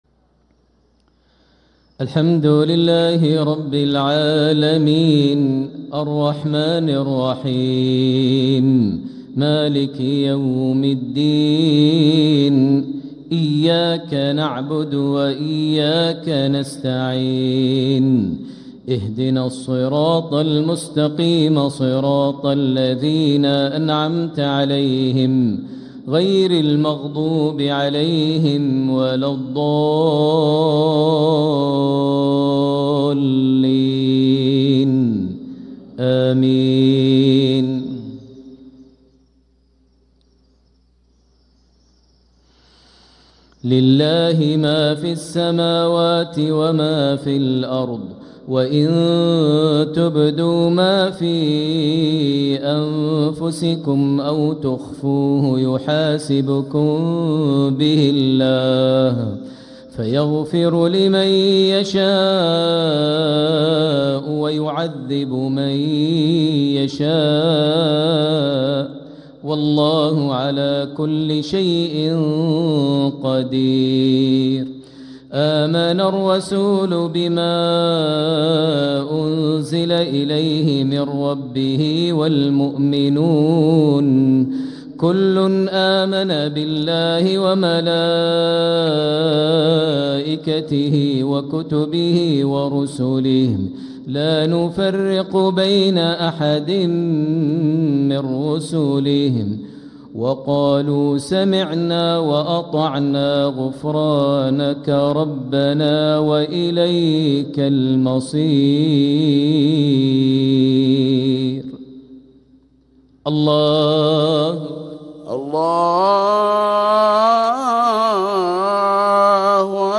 > 1446هـ > الفروض - تلاوات ماهر المعيقلي